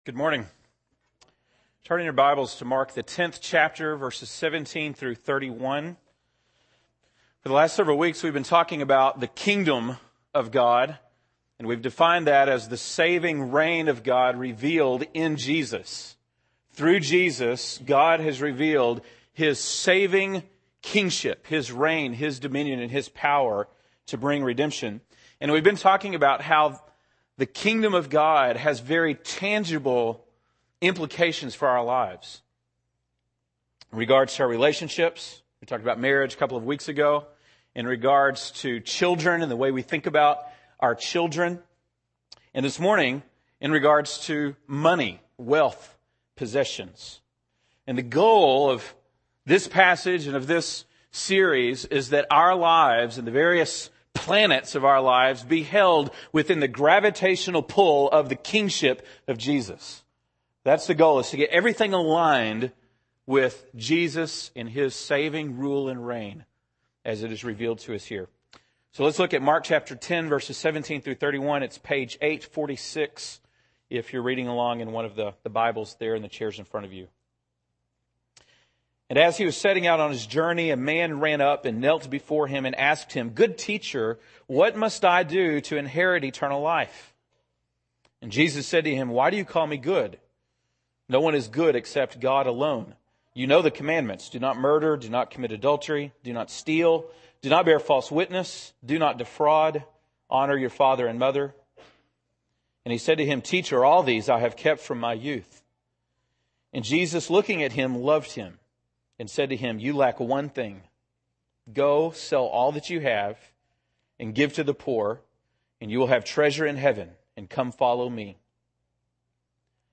August 3, 2008 (Sunday Morning)